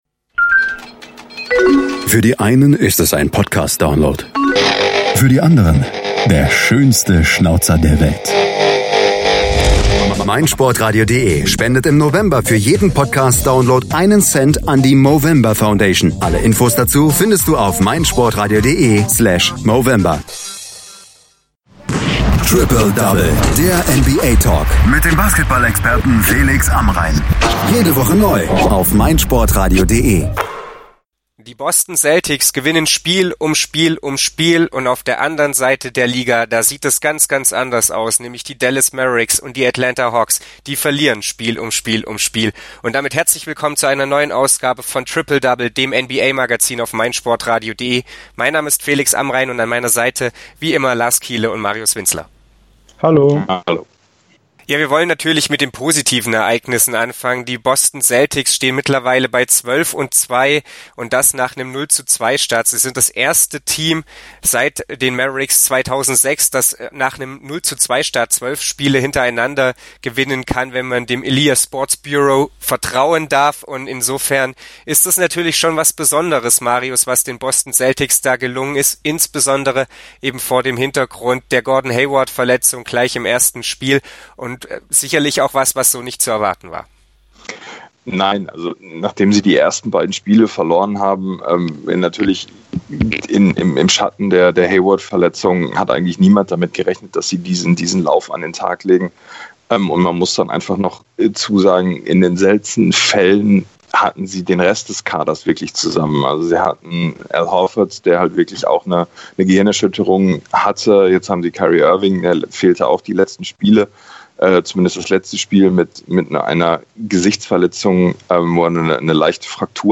diskutieren über die Beletage und Kellerbewohner der NBA.